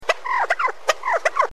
Die Farmerama Tierstimmen
Truthahn.wav.mp3